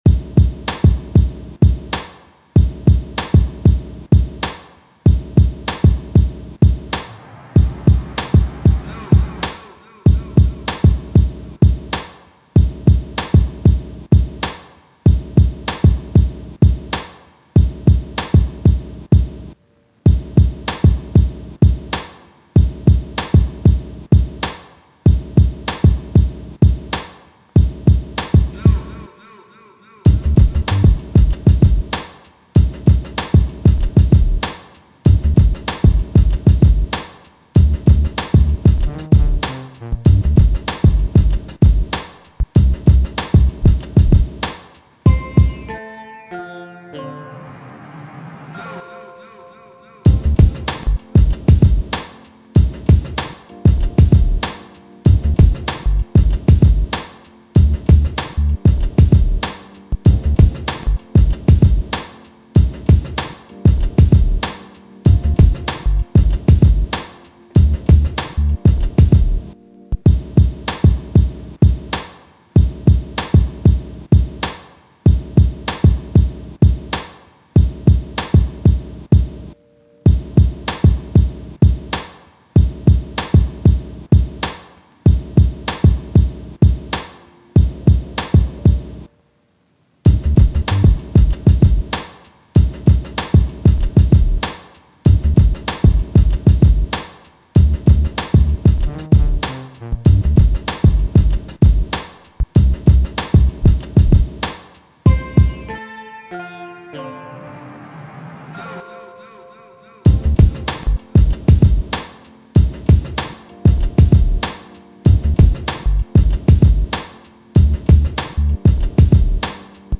R&B, 90s, Hip Hop
Gmin